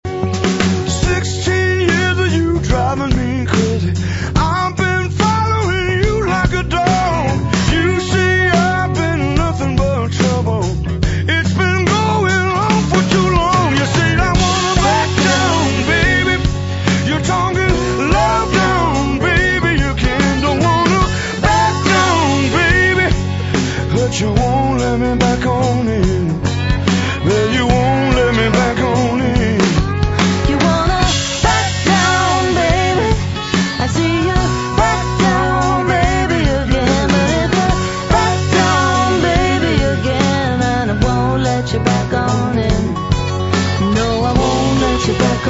あのオンリー・ワンの癒し系ヴォイスには何らの衰えも感じさせない。冬の日の暖かな日差しの様な１枚。